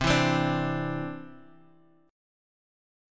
Cadd9 chord